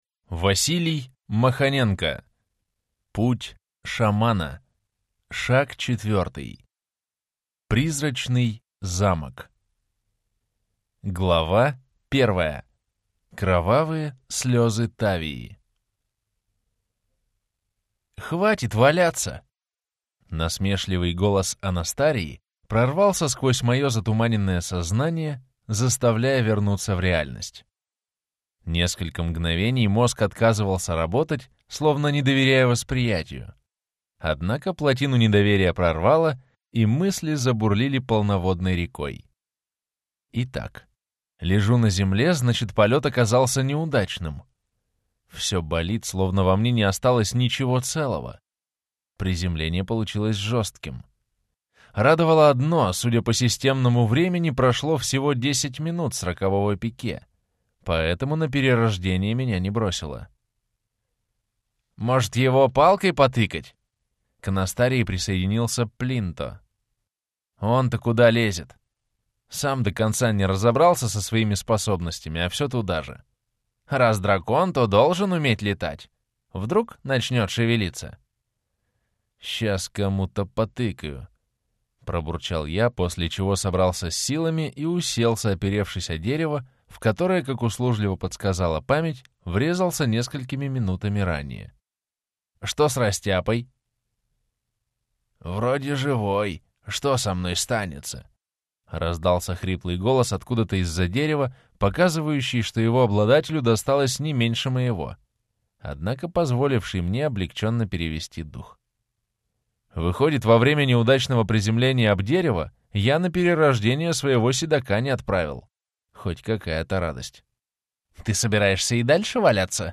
Аудиокнига Путь Шамана. Шаг 4. Призрачный замок | Библиотека аудиокниг